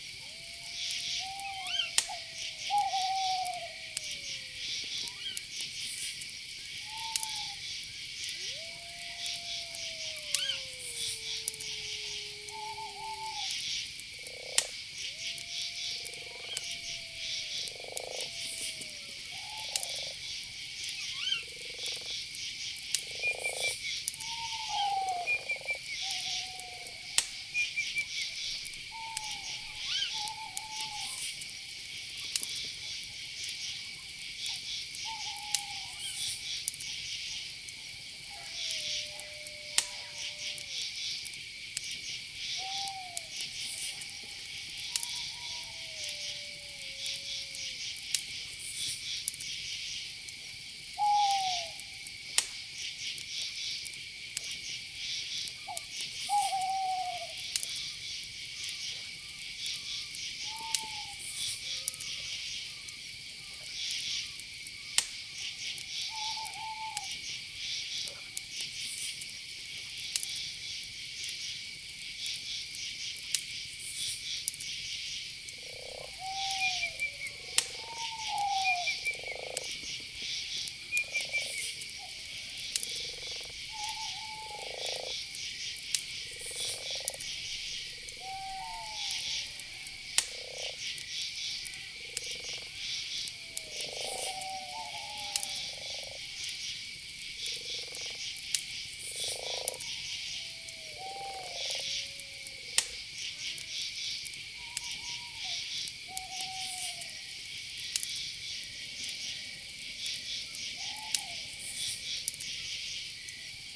campfireloop.wav